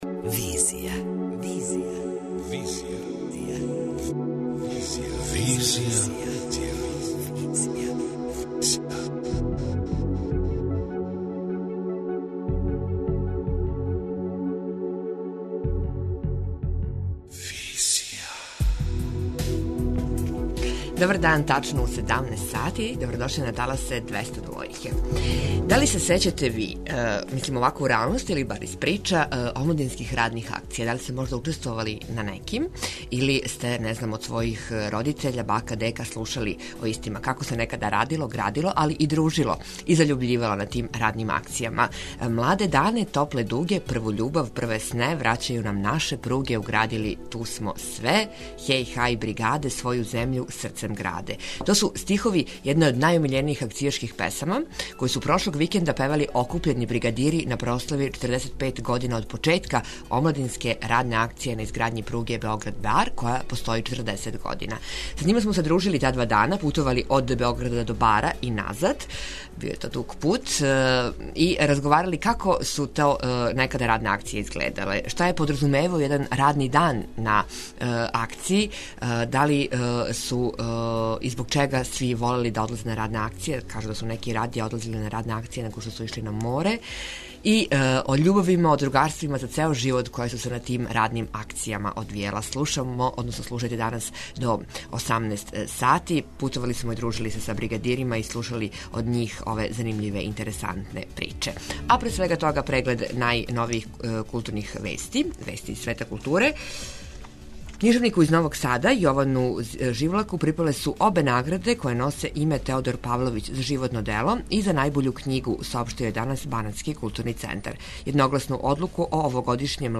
Са њима смо путовали пругом Београд-Бар и разговарали о омладинским радним акцијама, захваљујући којима је много изграђено у бившој Југославији. О томе како су на њима радили, како је изгледао један акцијашки дан, како су били мотивисани, али и о томе да су радне акције имале своје новине, радио-станице, културни и образовни програм, да су се на њима рађале љубави и другарства за цео живот.